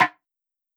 MetroPerc_MJ.wav